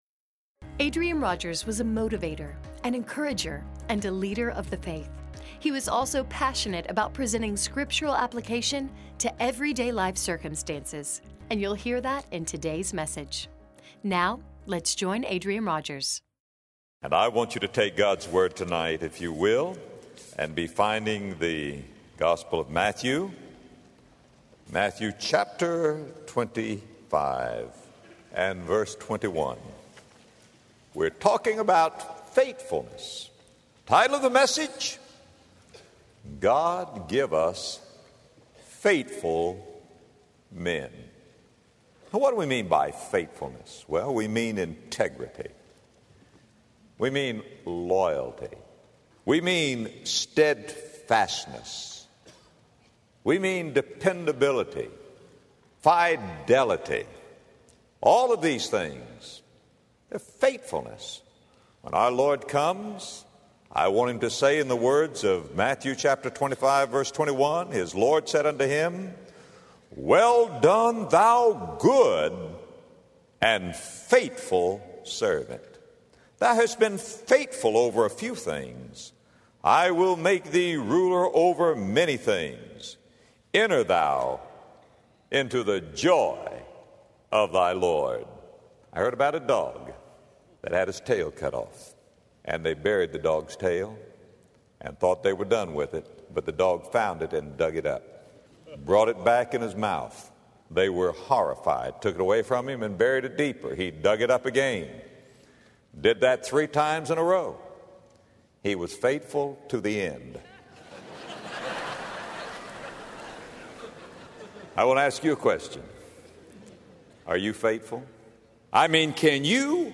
God Give Us Faithful Men Podcast with Adrian Rogers